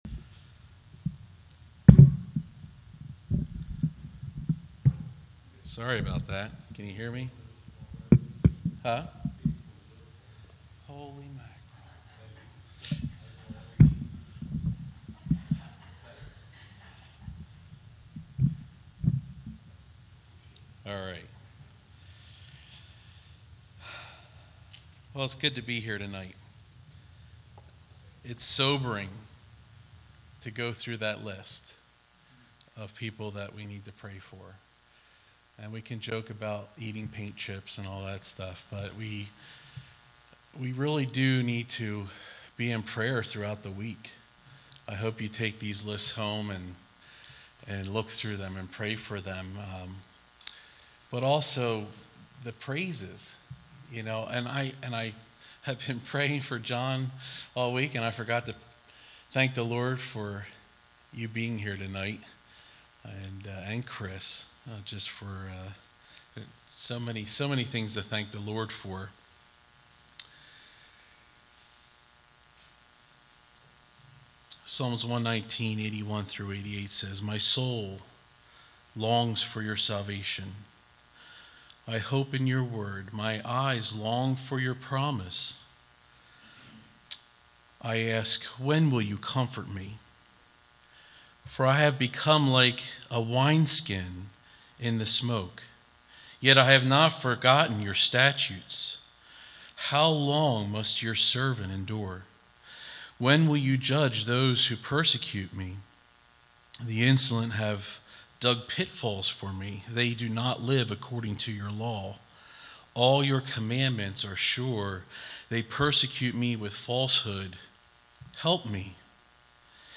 All Sermons Psalm 119:81-88